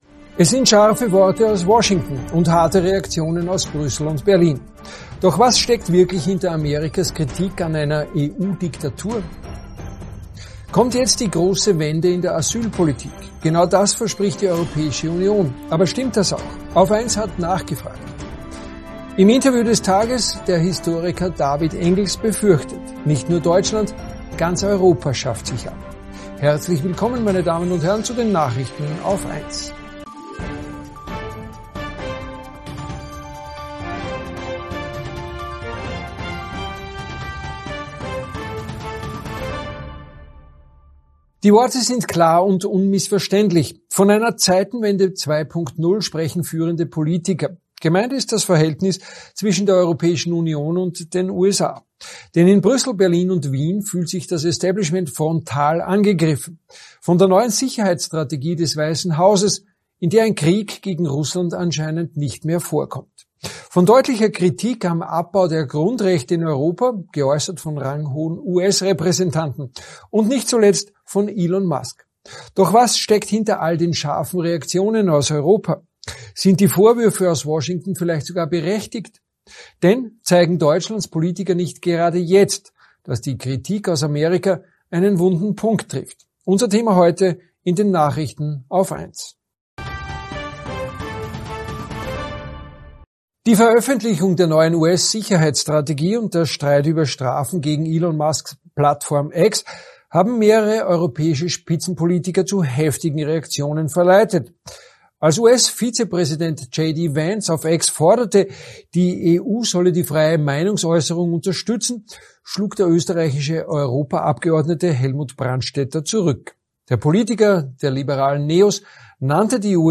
+ Und im Interview des Tages